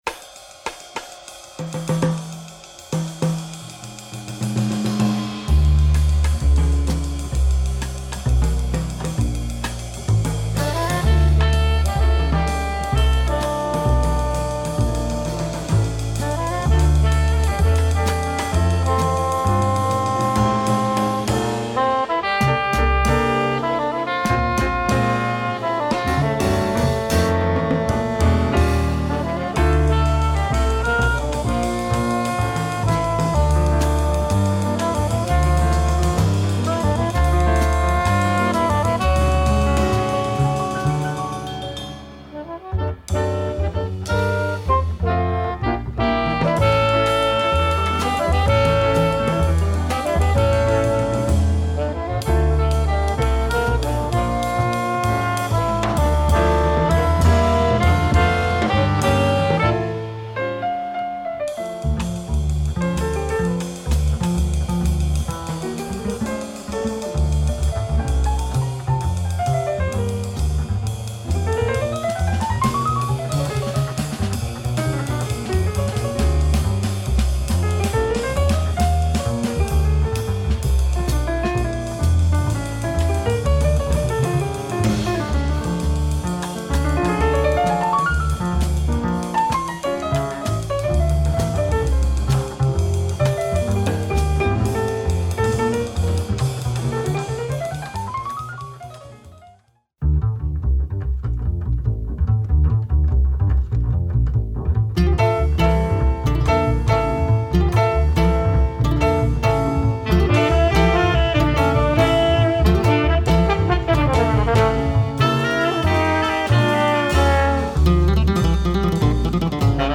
One of the best Spanish jazz albums